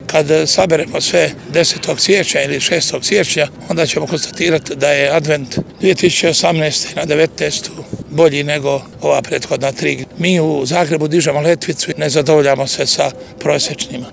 Manifestaciju je najavio i gradonačelnik Milan Bandić.